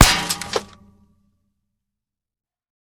sounds / material / bullet / collide / metall01gr.ogg
metall01gr.ogg